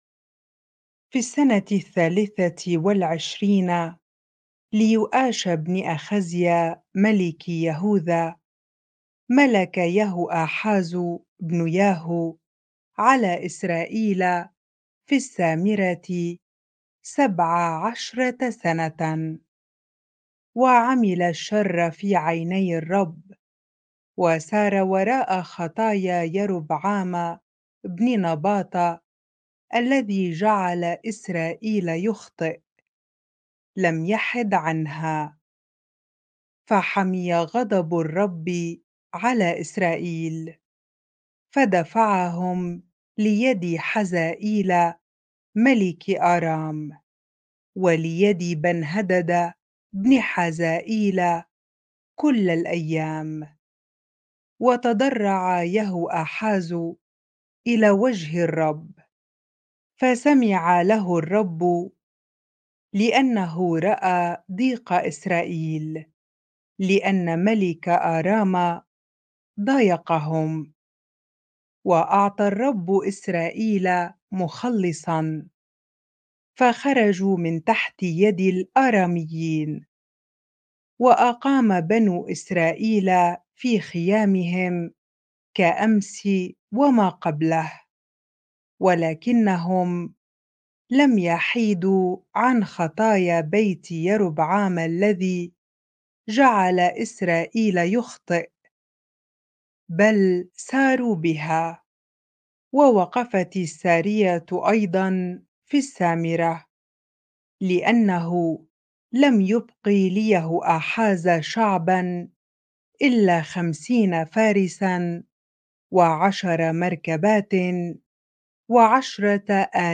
bible-reading-2 Kings 13 ar